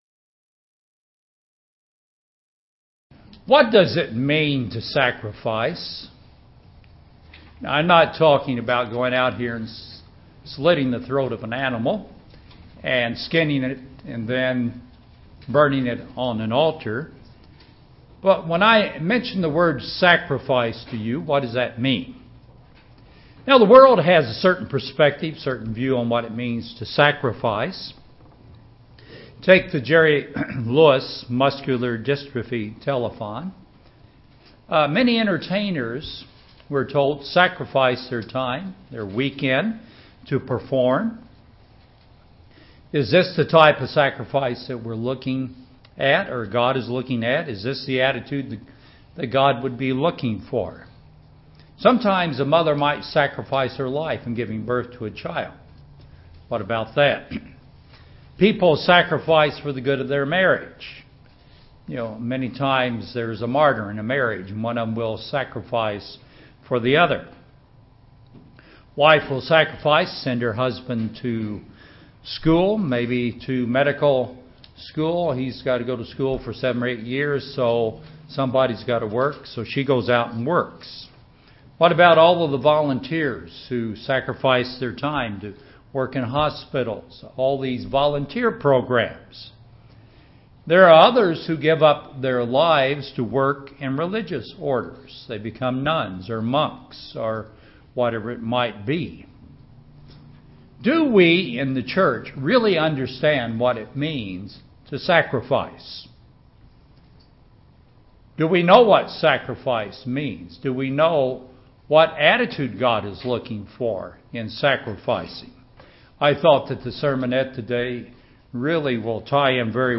What attitude does God want us to have in sacrificing? This sermon shows us the importance of being a living sacrifice.